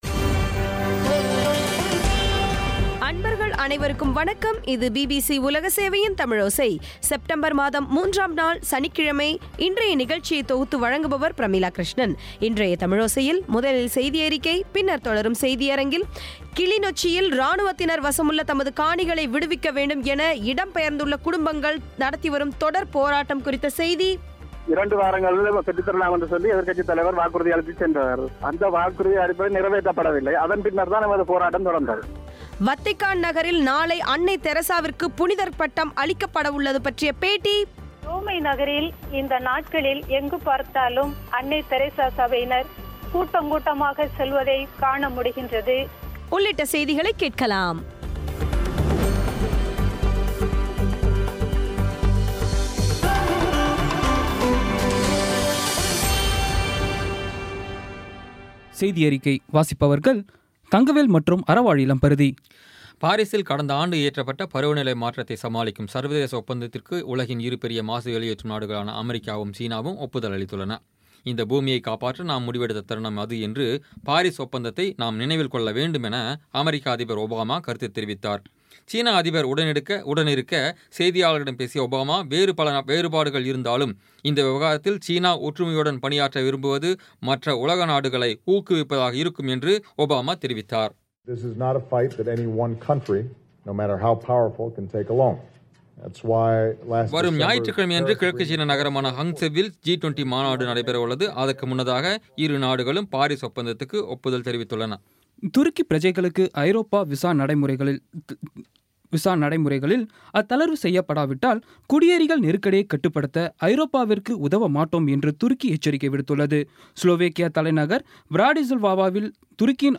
வத்திக்கான் நகரில் நாளை அன்னை தெரசாவிற்கு புனிதர் பட்டம் அளிக்கப்படவுள்ளது பற்றிய பேட்டி ஆகியவை கேட்கலாம்